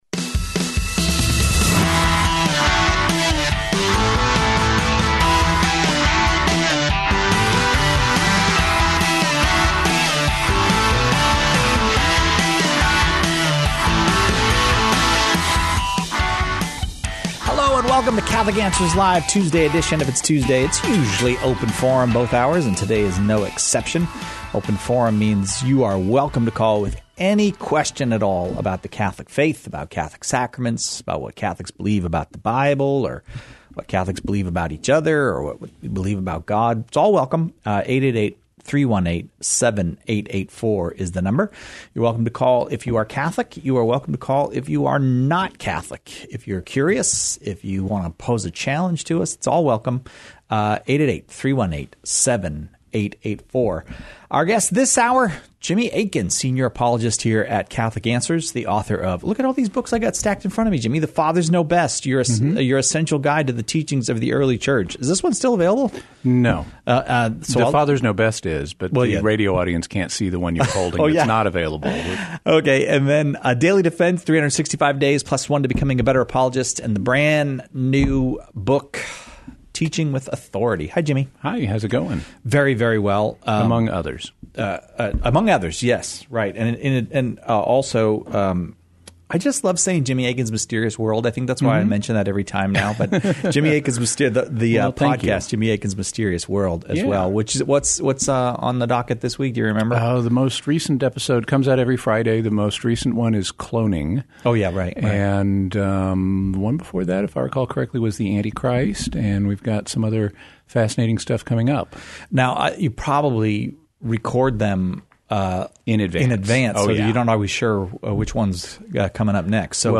Our guests grapple with questions on every aspect of Catholic life and faith, the moral life, and even philosophical topics that touch on general religious beli...